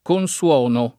konSU0no] — in tutta la coniug., U0 se tonico, ‑o‑ (meglio che Uo‑) se atono — un es. poet. di -0- per -U0-: Che l’abbia data a Namo mi consona [